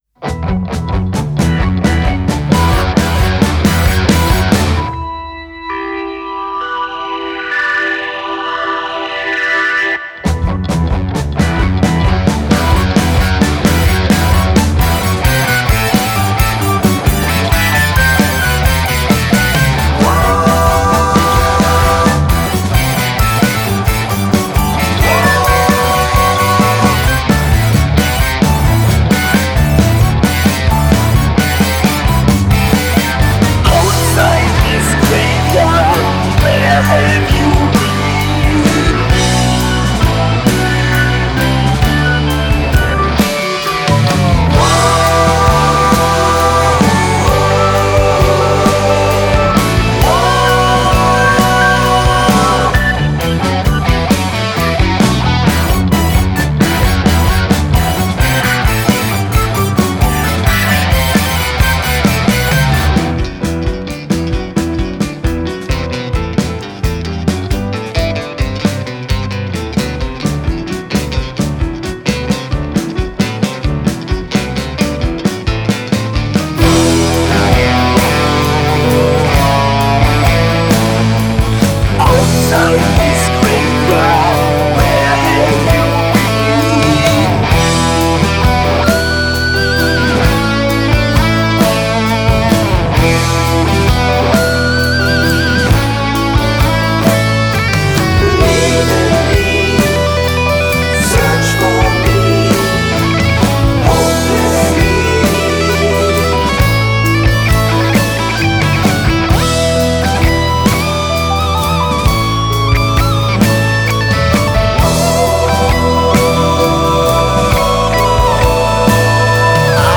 Instrument & Musikgenre: ▷ BASS, Rock